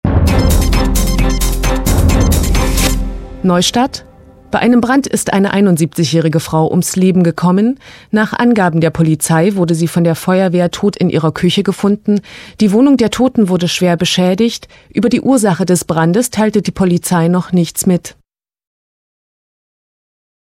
Hörbeispiel 5 „Melodie“ glocke
5-Melodie.mp3